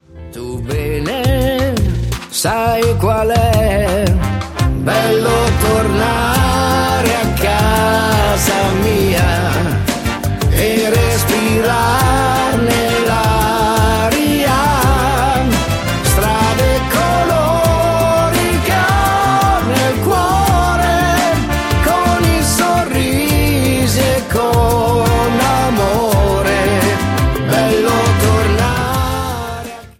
MODERATO  (04,03)